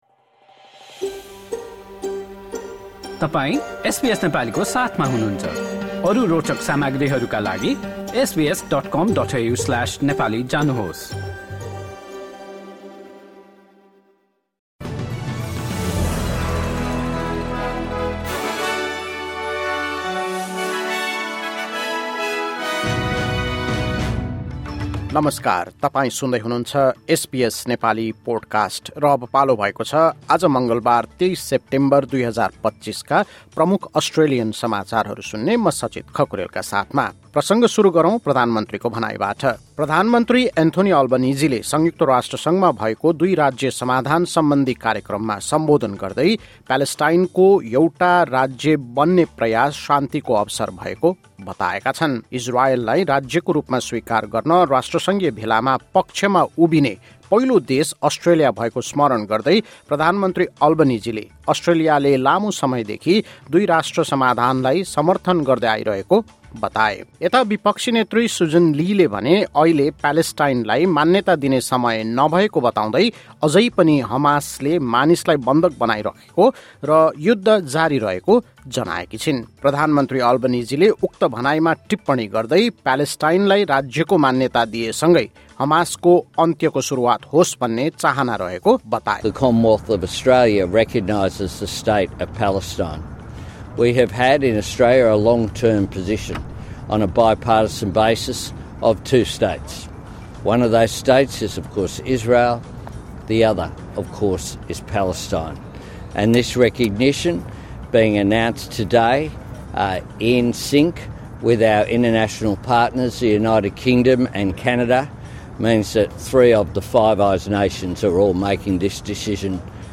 एसबीएस नेपाली प्रमुख अस्ट्रेलियन समाचार: मङ्गलवार, २३ सेप्टेम्बर २०२५